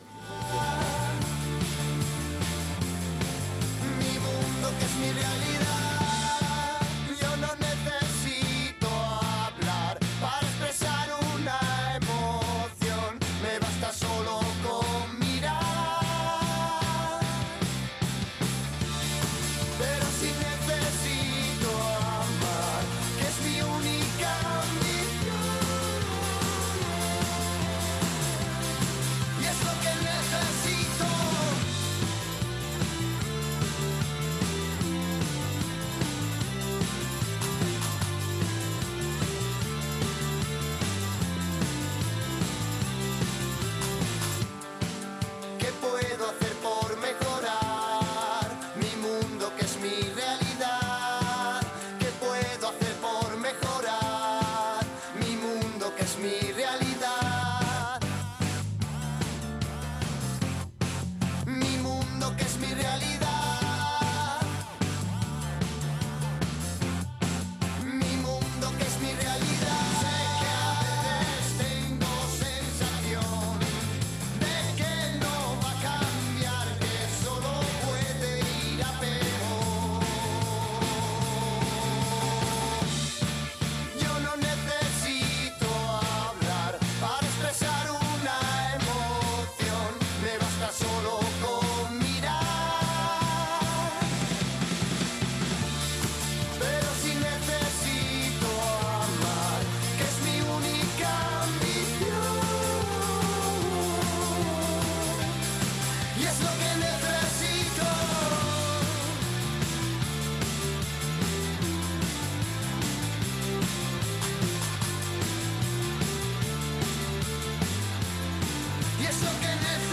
Día de partido y de tertulia.